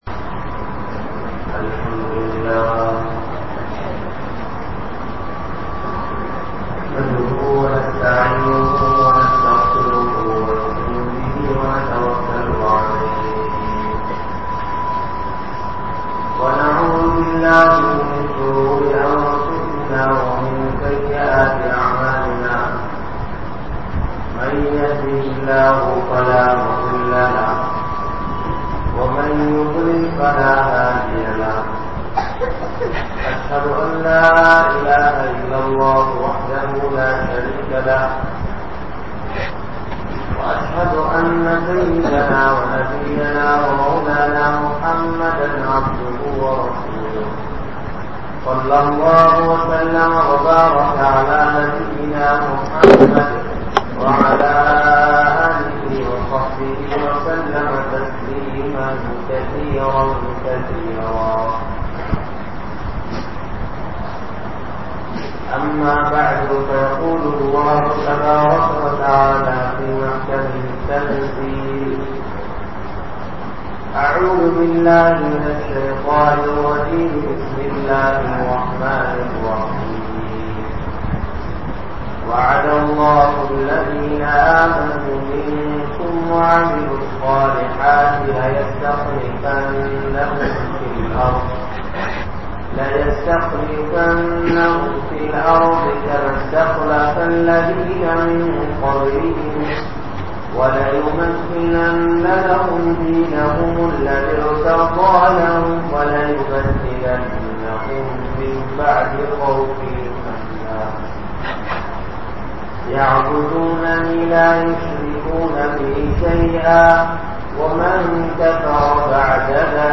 Ungalai Paathuhaappavan Yaar? (உங்களை பாதுகாப்பவன் யார்?) | Audio Bayans | All Ceylon Muslim Youth Community | Addalaichenai
Mavadippalli Markaz